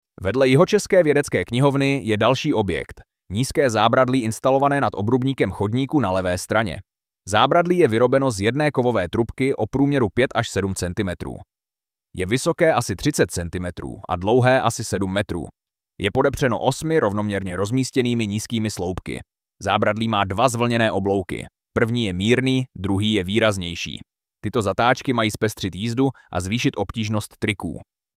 AUDIOPOPIS ZÁBRADLÍ NA ZEMI